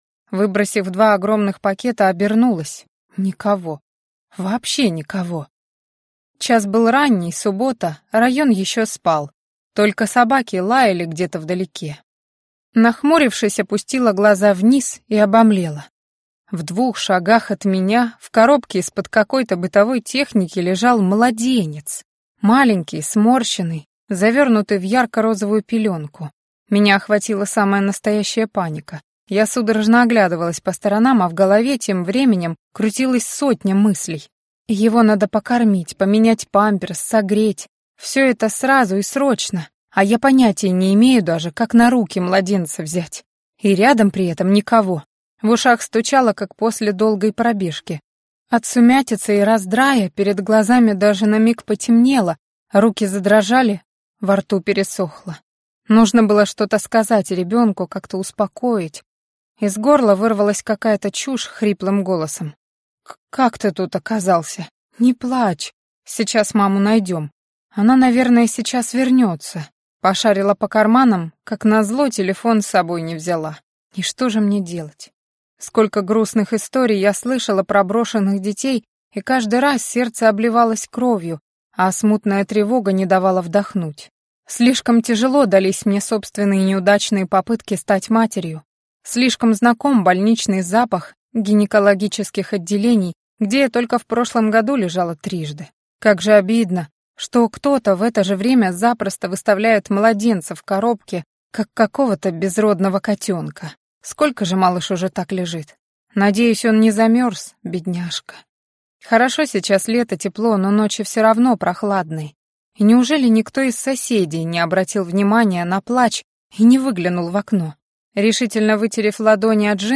Аудиокнига Крылатым не входить!